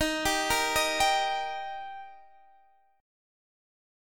D# Chord
Listen to D# strummed